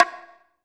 1 perc -bongo.wav